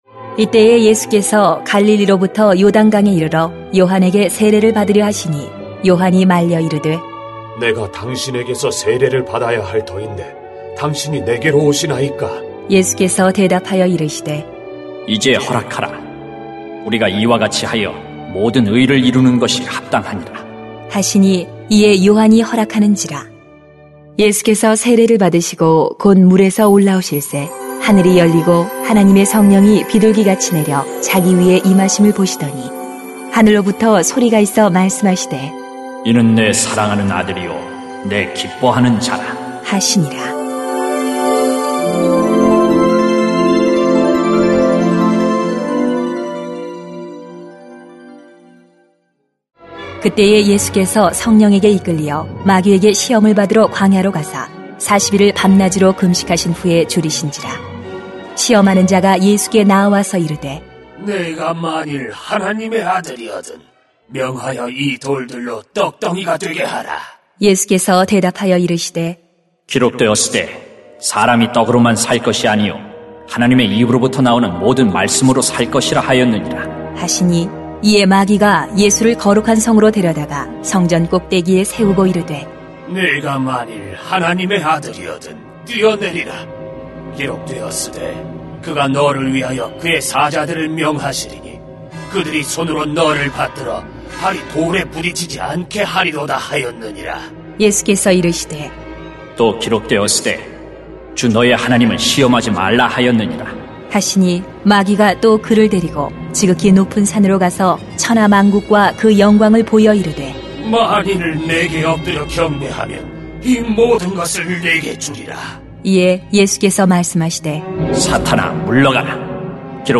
[마 3:13-4:11] 모든 의를 이루어야 합니다 > 새벽기도회 | 전주제자교회